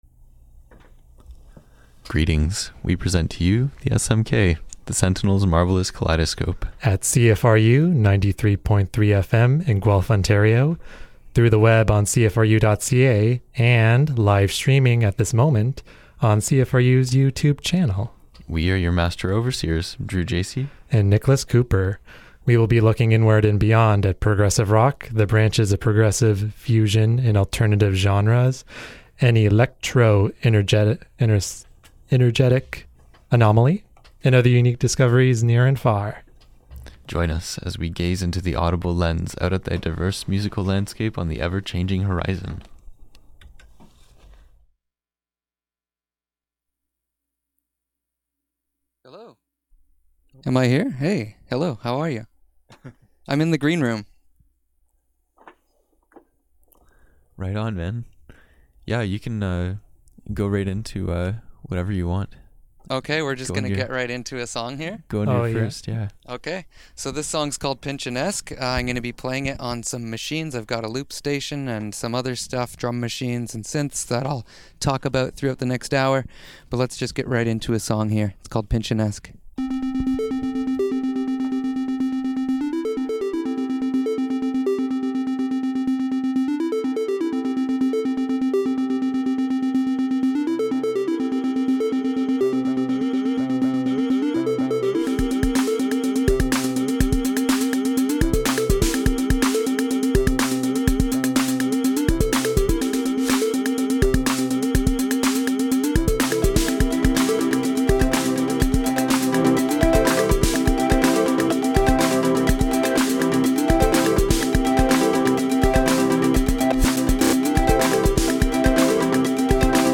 Looking inward and beyond at progressive, fusion and alternative genres, near and far...